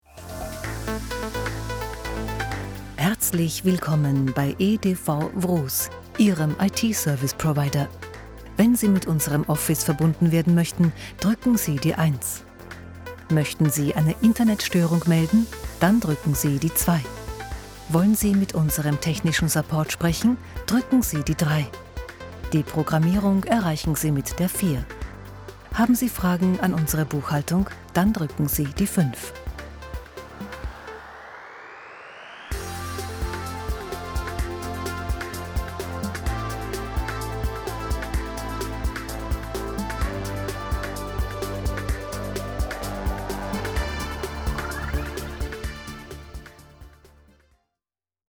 Voiceover | Stimme
Telefonband Hörbeispiele in Eigenproduktion: